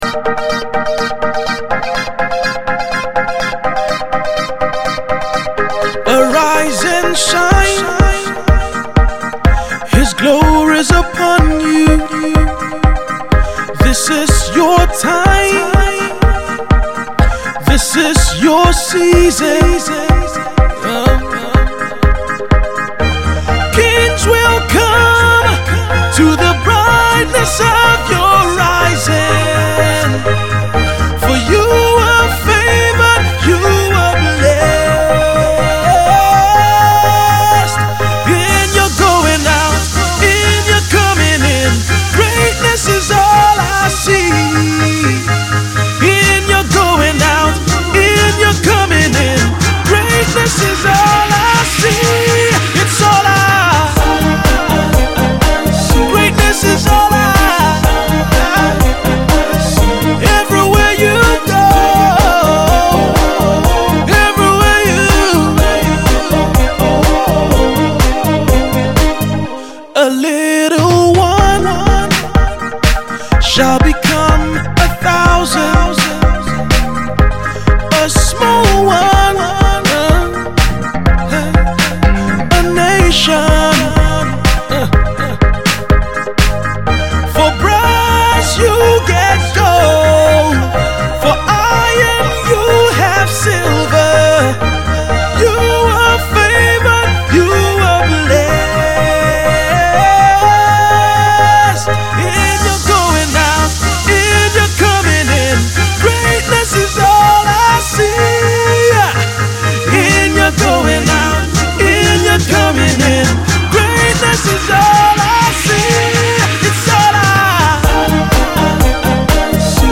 beautiful tune
prophetic record